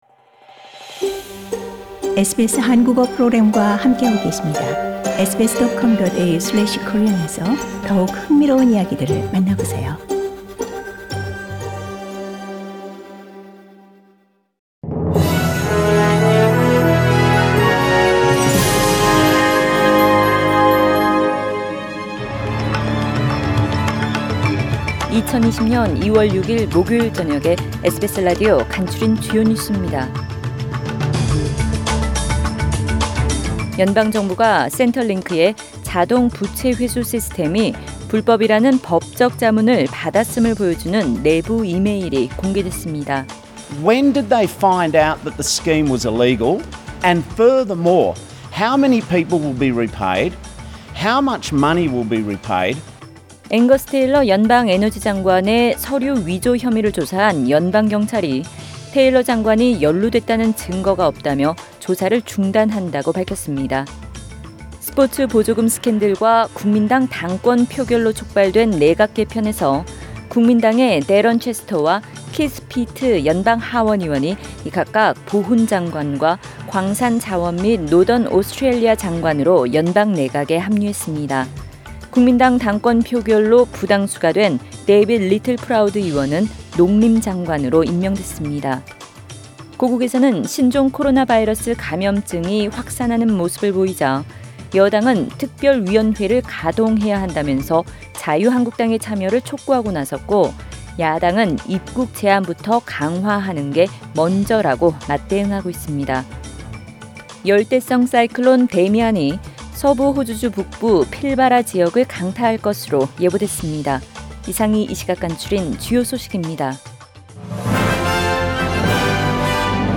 Major stories from SBS Korean News on Thursday 6 February